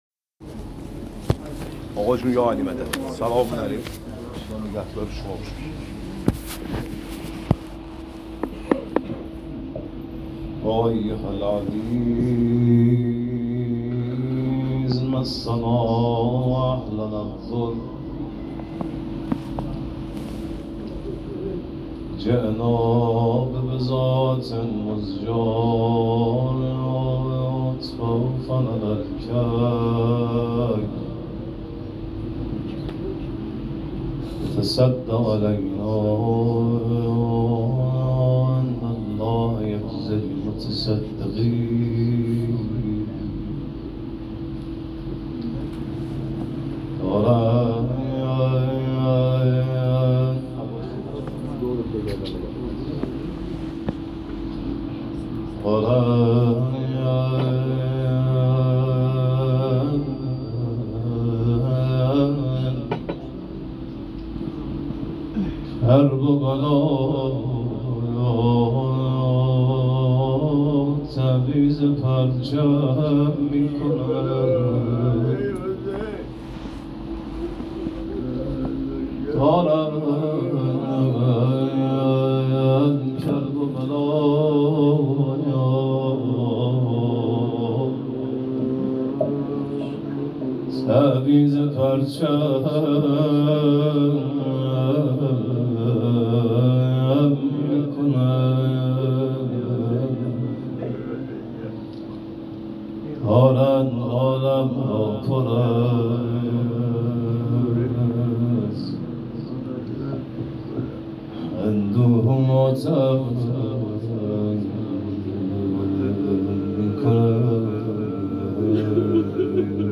روضه شب اول